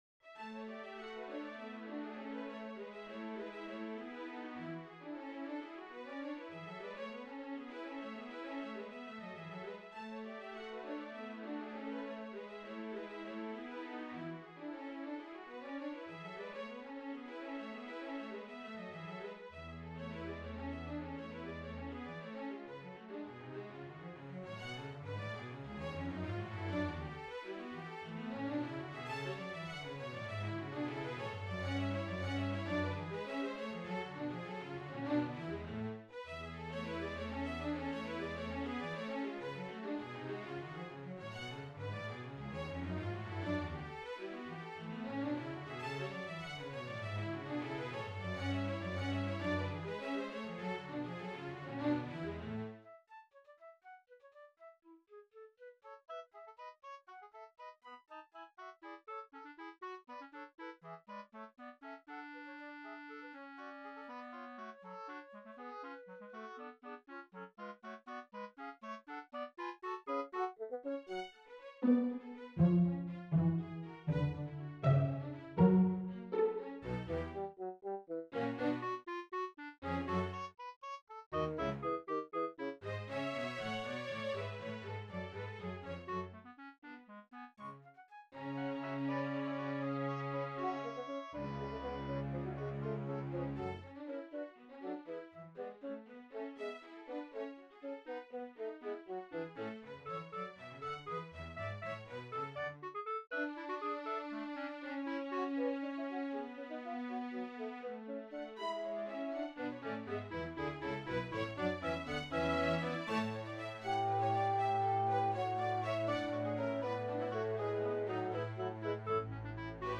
fl�te hautbois clarinette en ♭si basson
kleineFugue.wav